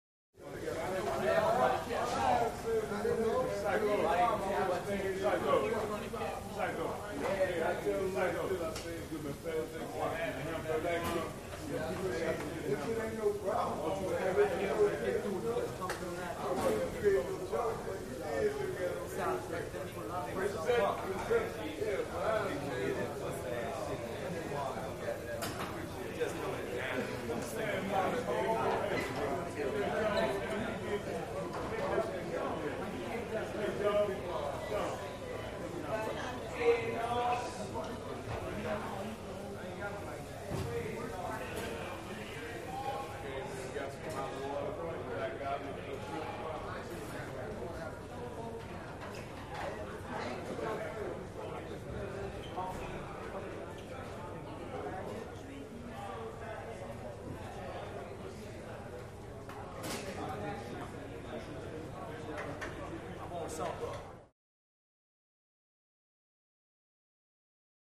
Prison Mess Hall; Large Crowd Busy Male Voices In A Large Cafeteria With Light Background Activity, Time Bell Rings; Medium Perspective.